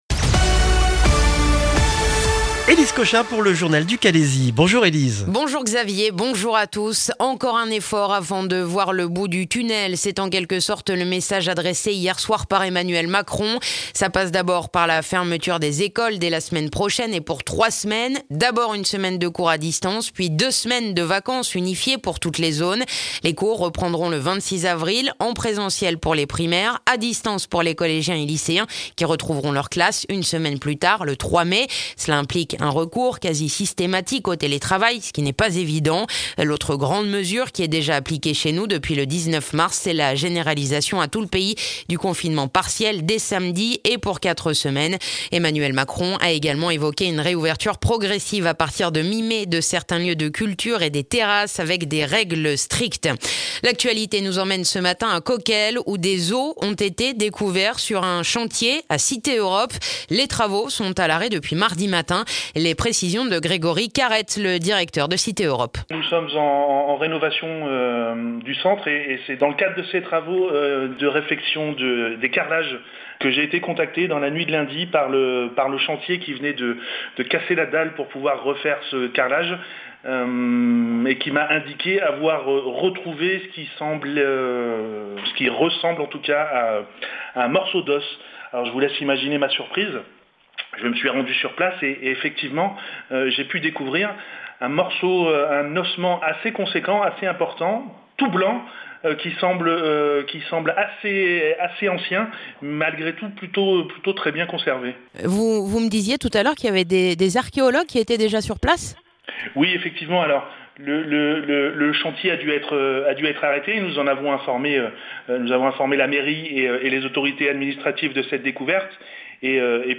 Le journal du jeudi 1er avril dans le calaisis